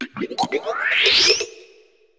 Cri de Floréclat dans Pokémon HOME.